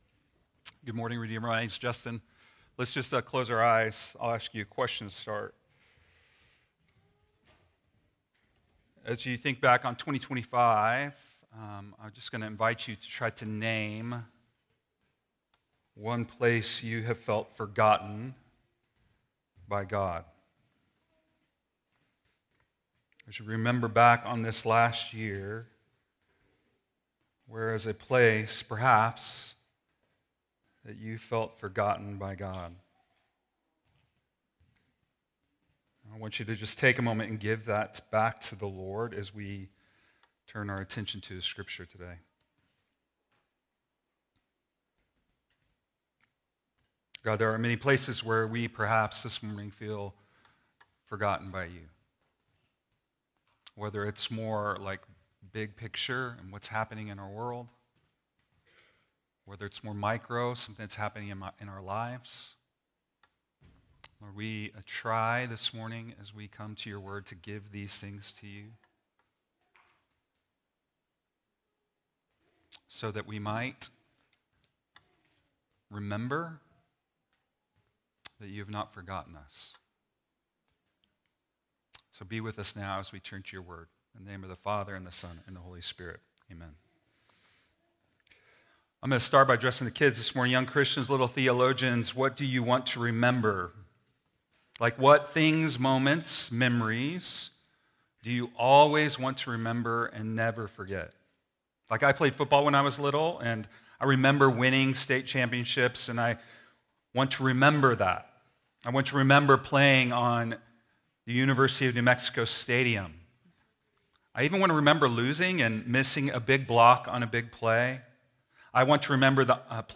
1.4 sermon - Made with Clipchamp.m4a